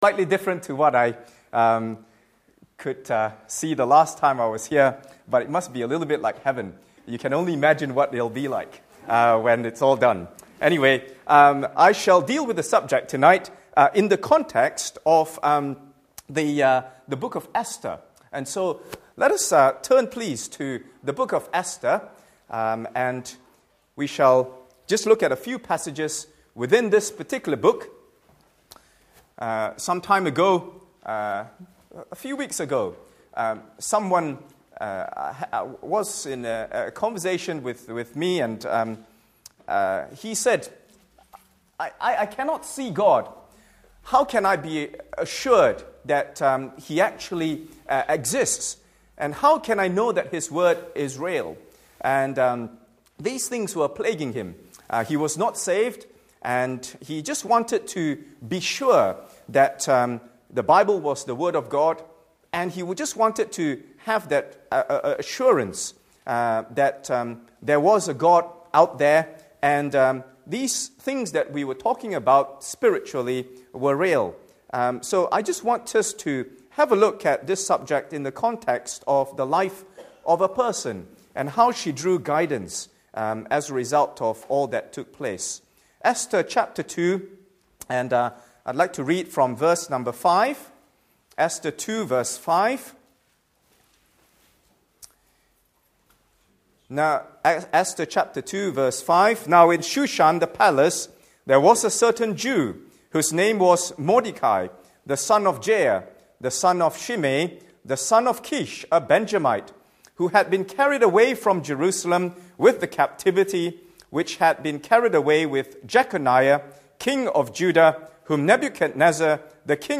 This evening we enjoyed the first of our winter series of Monthly Conferences with the overall topic of ‘Important Spiritual Issues for believers today‘.